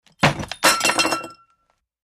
Thud of fist on table, with clatter